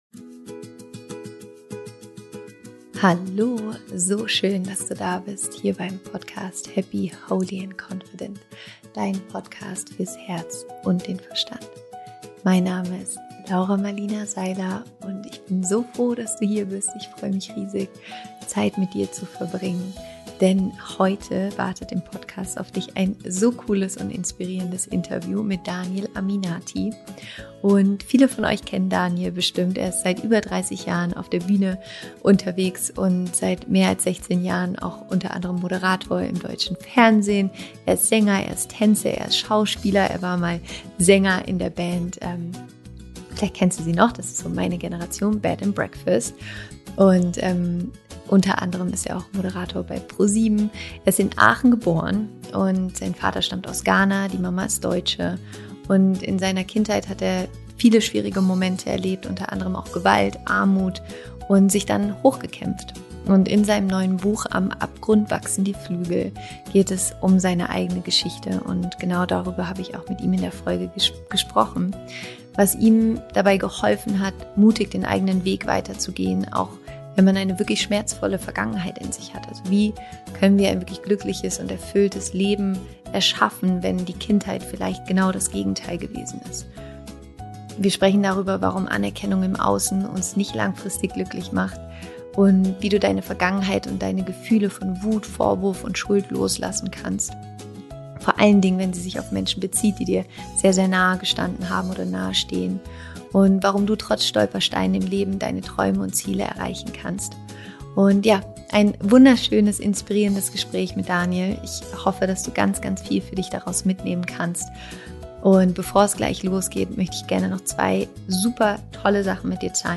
Genau darüber spreche ich heute mit Daniel Aminati im Podcast.